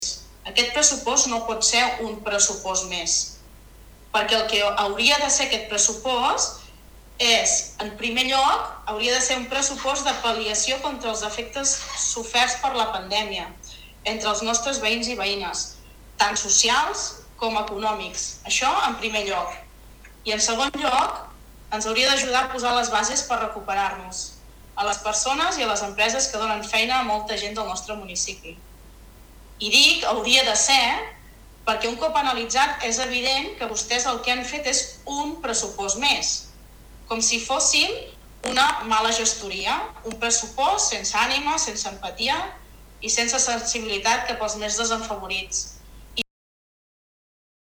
Una de les regidores de l’oposició que va queixar-se en aquest sentit va ser Sandra Bartomeus, de Junts, que durant el Ple de dijous va qualificar el pressupost de “poc empàtic” i “sense ànima”.